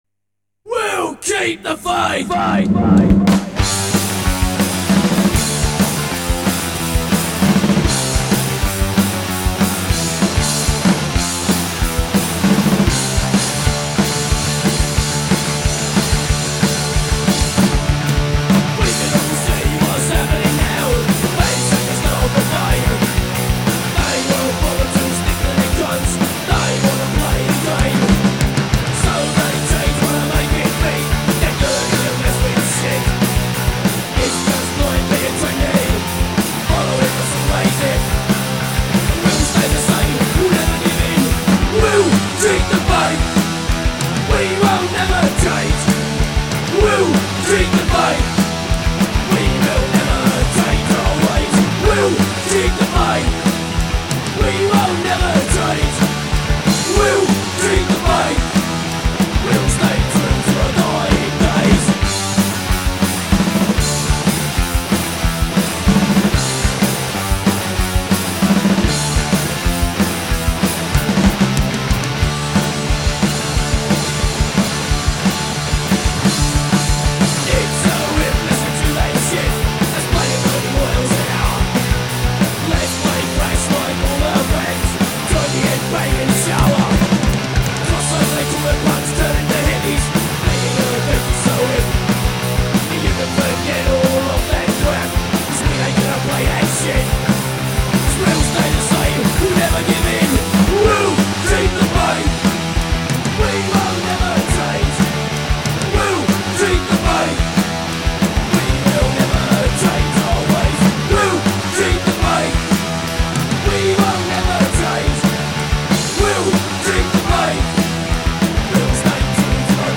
Oi Punk
Oi! Band